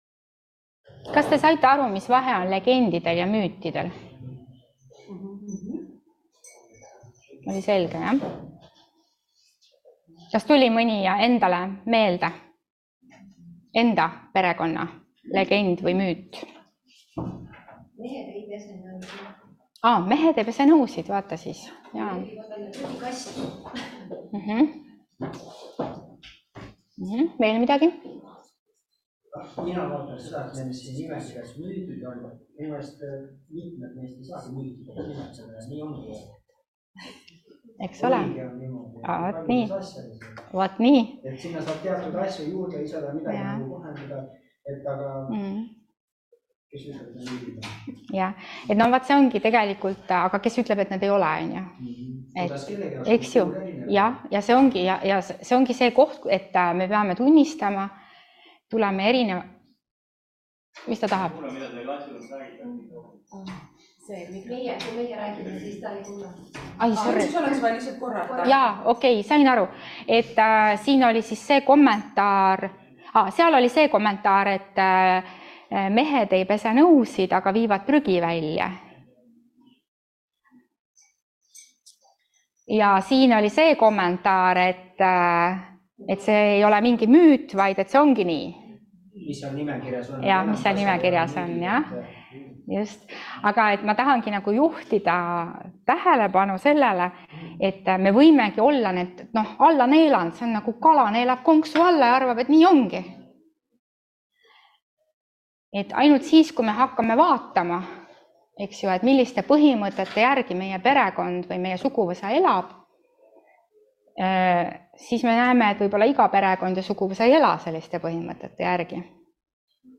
Perenõustamine. 4. loeng [ET] – EMKTS õppevaramu